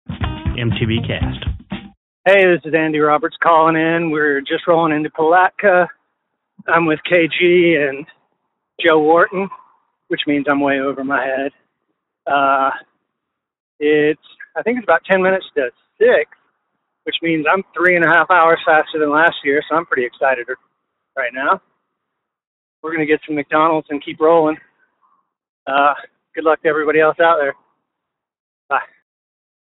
called in from Palatka!